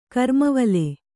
♪ karmavale